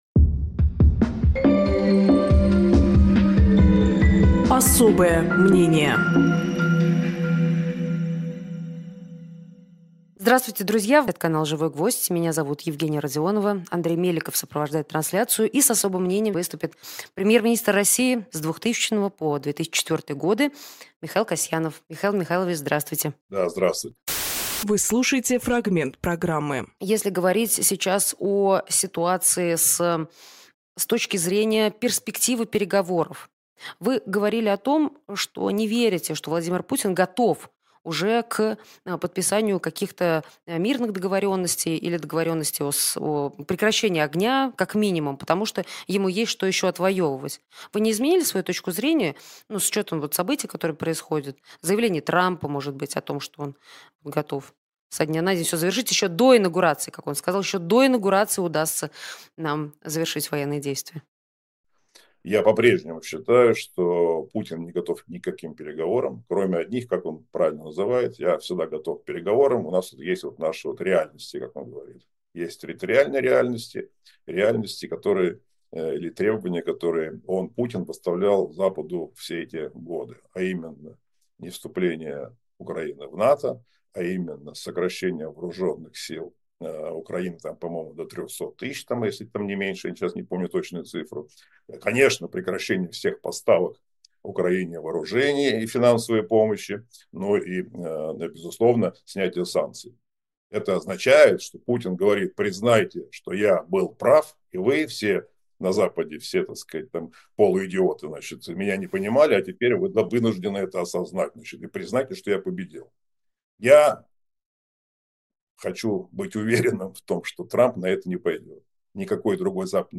Михаил Касьяновполитик, председатель «Партии народной свободы»
Фрагмент эфира от 17.12.24